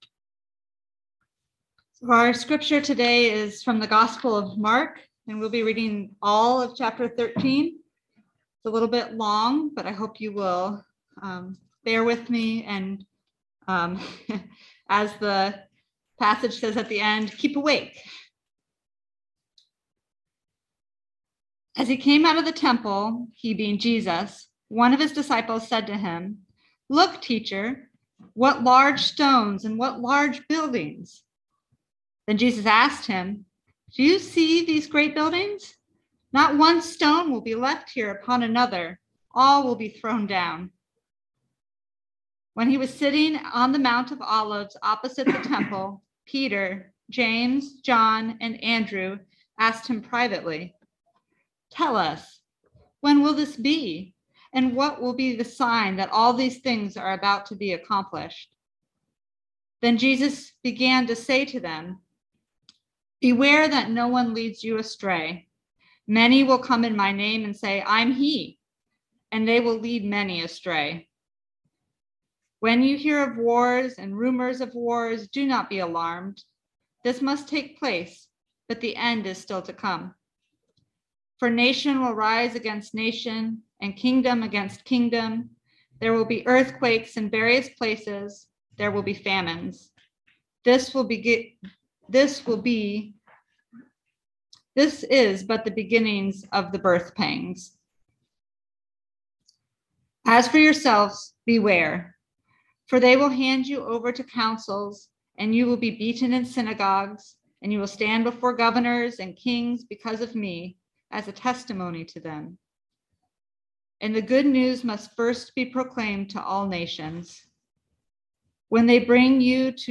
Listen to the most recent message from Sunday worship at Berkeley Friends Church, “Stay Awake.”